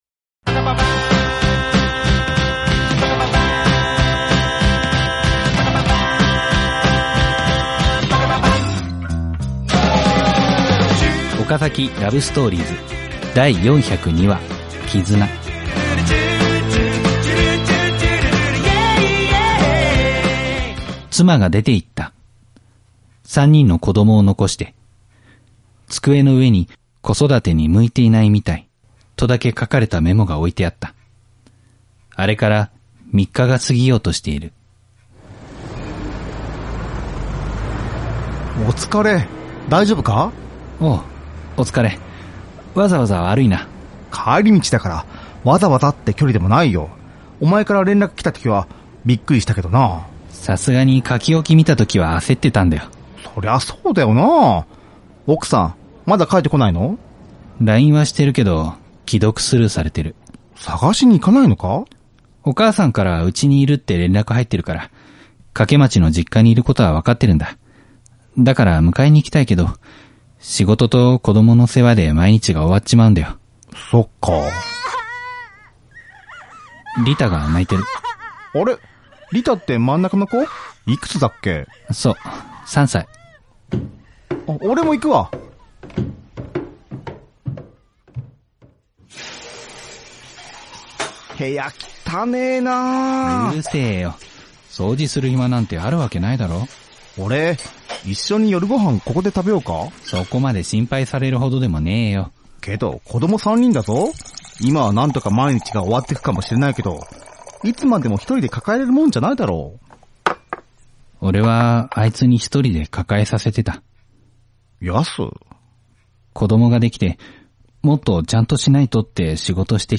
身近でリアルな恋愛をドラマにしてお送りする「OKAZAKI LOVE STORIES」。